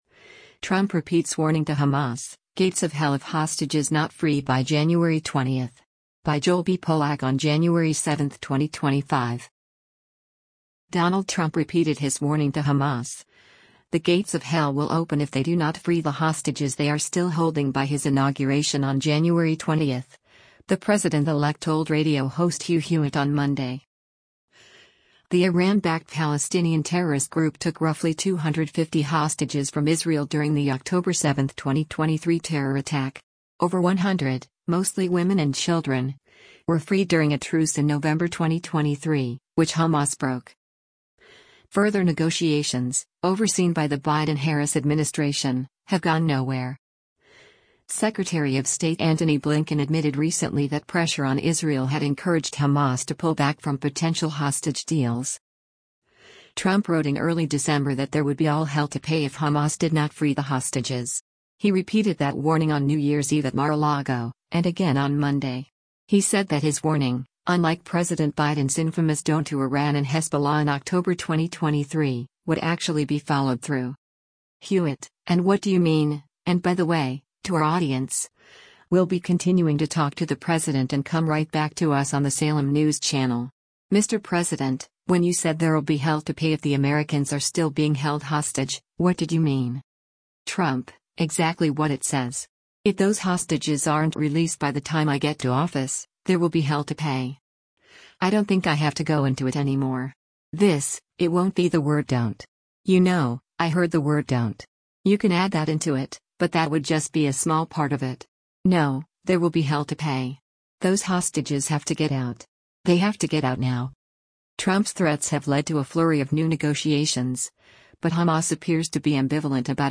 Donald Trump repeated his warning to Hamas: the “gates of hell” will open if they do not free the hostages they are still holding by his Inauguration on January 20, the President-elect told radio host Hugh Hewitt on Monday.